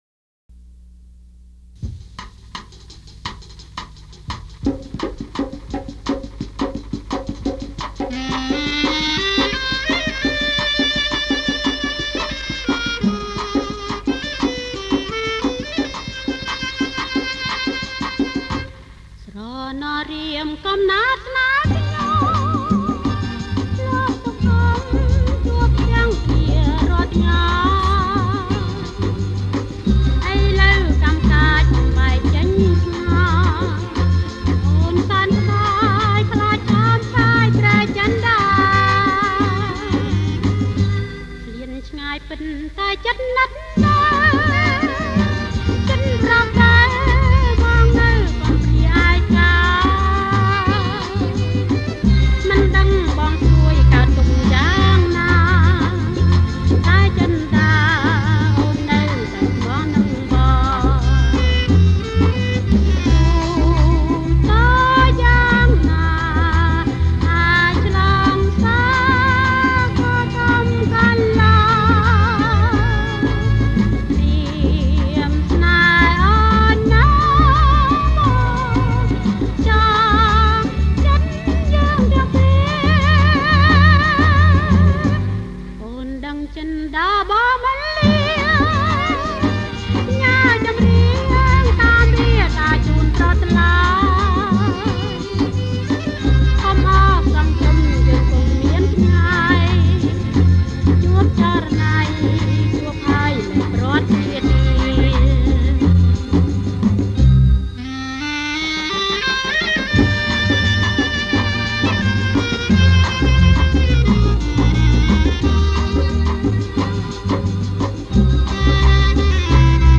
ចេញផ្សាយជាកាស្សែត (Cassette)